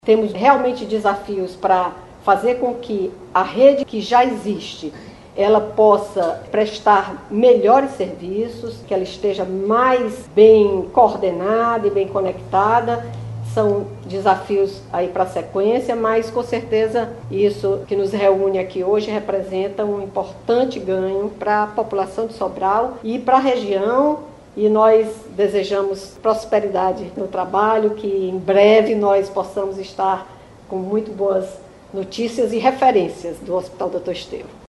A governadora Izolda Cela destacou a importância da iniciativa para a saúde, não só de Sobral, mas dos municípios vizinhos que também vão poder usufruir do equipamento.